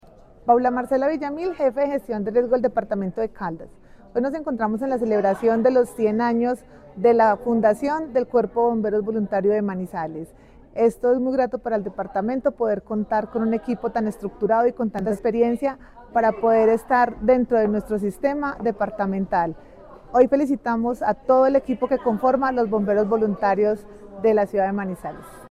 La Secretaría de Medio Ambiente de Caldas y su Jefatura de Gestión del Riesgo, participó en la ceremonia conmemorativa por los 100 años del Benemérito Cuerpo de Bomberos Voluntarios de Manizales, una de las instituciones emblemáticas del departamento por su compromiso con la atención de emergencias y la protección de la vida.
Paula Marcela Villamil Rendón, jefe de Gestión del Riesgo de Caldas